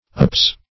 Apse \Apse\ ([a^]ps), n.; pl.